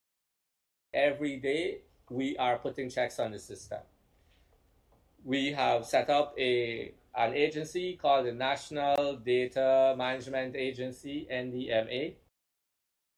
This assurance was recently provided by Minister of Public Service and Government Efficiency, Zulfikar Ally, when he spoke at a public meeting in Region Six over the weekend.